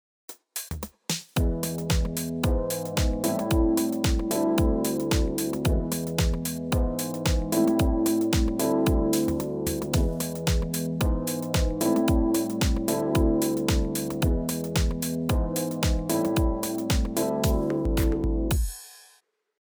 ドラムには使わないので、バランス的にドラムを小さめにしています。
とりあず、プリセットそのままの音
サウンドは確かに広がったし艶が出ました。